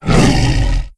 c_sibtiger_atk2.wav